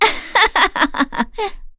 Erze-Vox_Happy1.wav